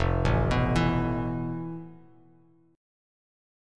FM#11 chord